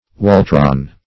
\Wal"tron\